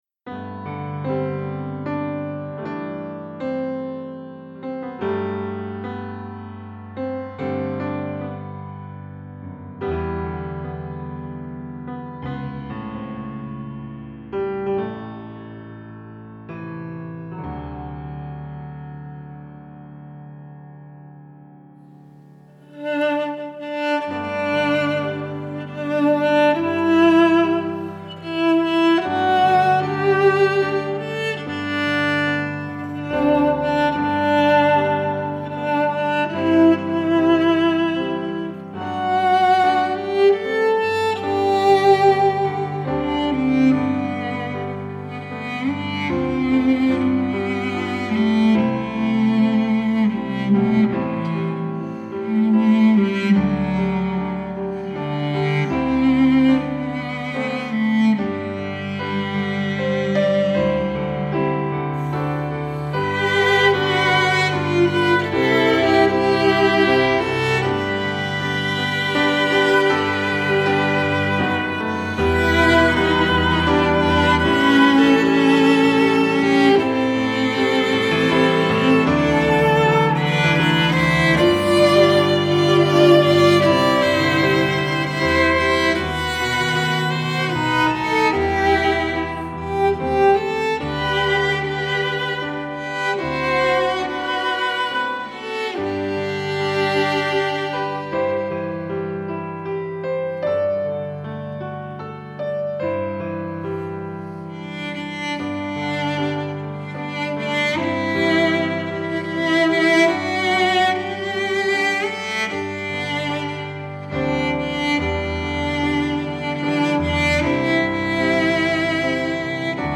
Classical
Viola, Cello, Piano, all of instrument were recorded live recording way and real instrument. Piano was used master keyboard.